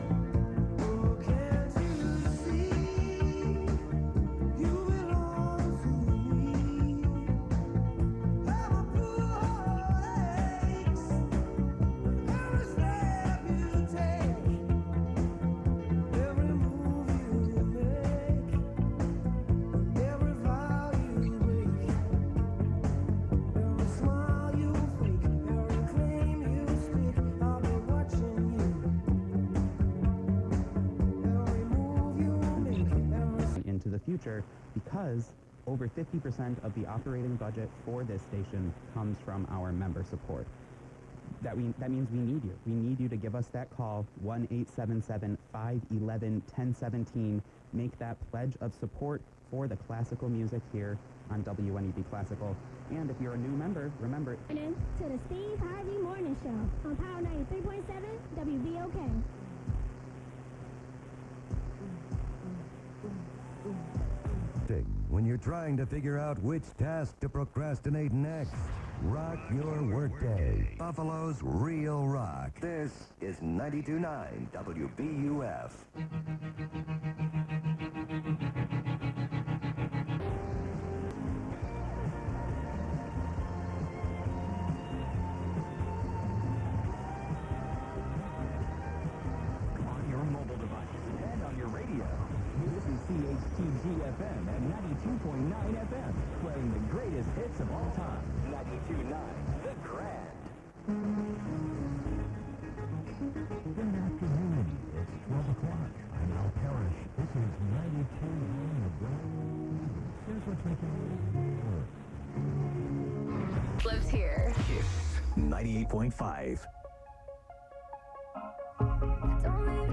Takes a bit of antenna fiddling but nearly all the stations mentioned did come in :
Buff Radio Scan
97 Rock 96.9 - playing the Police but no station ID
The Grand 92.9 ( with a twist of the antenna)
90.9 WLNF ( a very weak signal of this community station from Lockport NY)